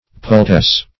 Pultesse \Pul"tesse\